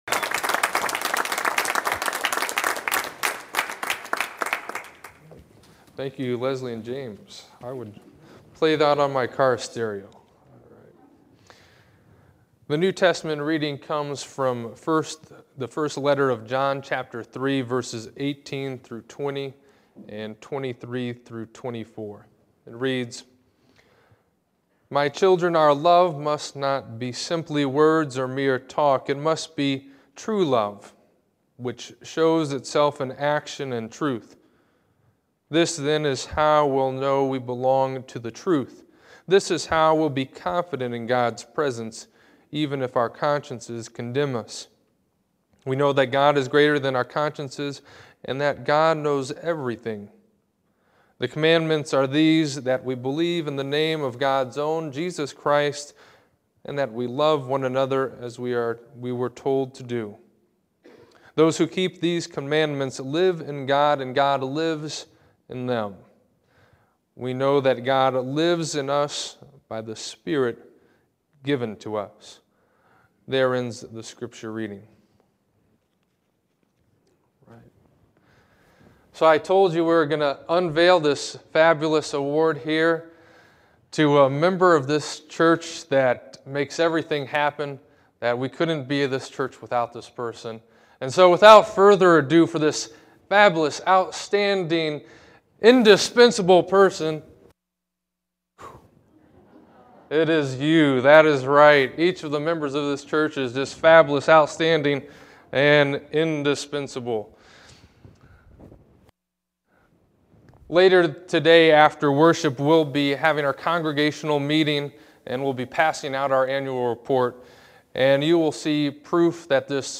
This sermon considers the claim that whether one has a spiritually fulfilling life has in large part to do with whether one has engrained spiritual habits.